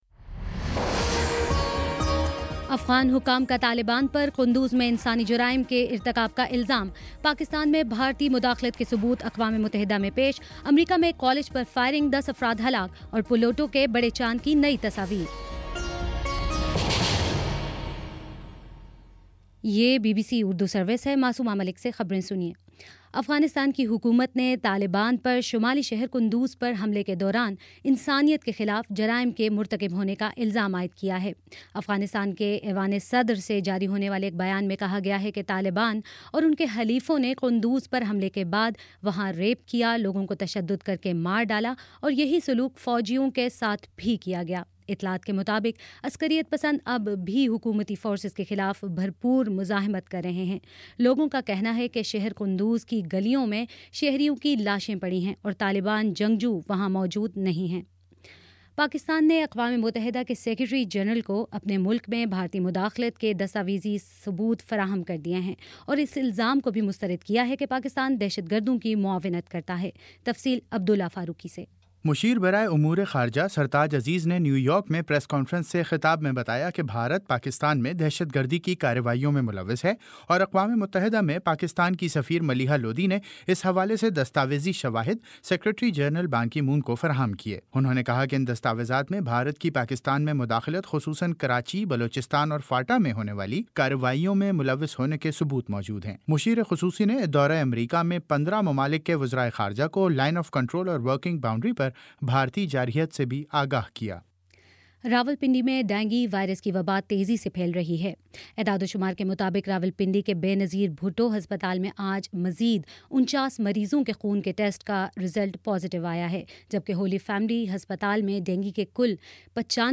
اکتوبر 02 : شام پانچ بجے کا نیوز بُلیٹن